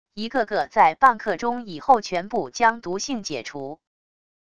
一个个在半刻钟以后全部将毒性解除wav音频生成系统WAV Audio Player